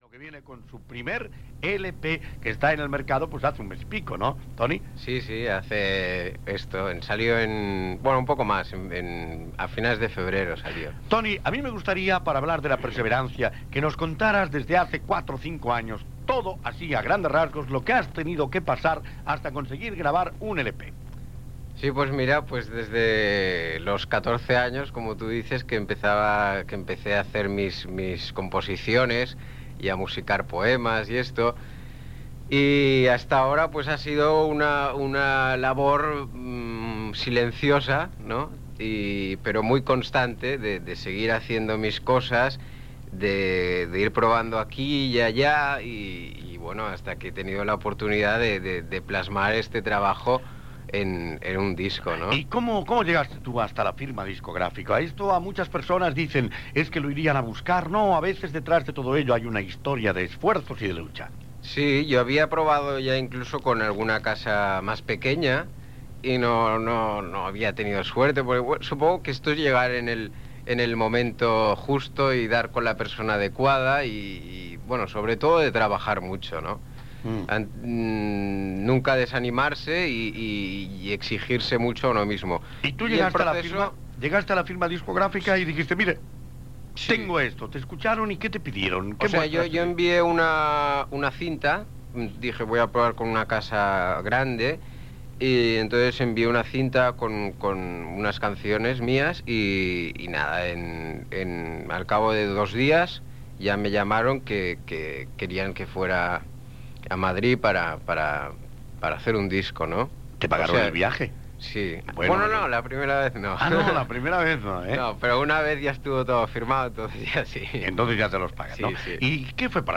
Magazine matinal
Entrevista